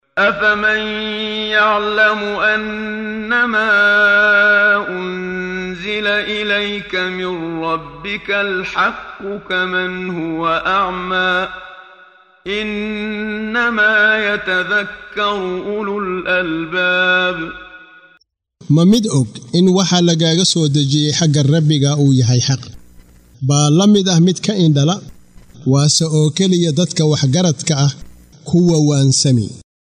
Waa Akhrin Codeed Af Soomaali ah ee Macaanida Suuradda Ar-Racad ( Onkodka ) oo u kala Qaybsan Aayado ahaan ayna la Socoto Akhrinta Qaariga Sheekh Muxammad Siddiiq Al-Manshaawi.